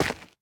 Minecraft Version Minecraft Version snapshot Latest Release | Latest Snapshot snapshot / assets / minecraft / sounds / block / basalt / step3.ogg Compare With Compare With Latest Release | Latest Snapshot
step3.ogg